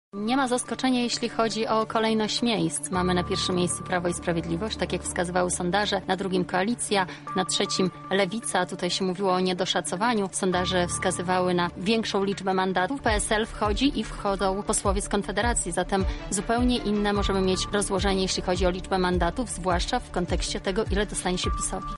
obecna w naszym studiu wyborczym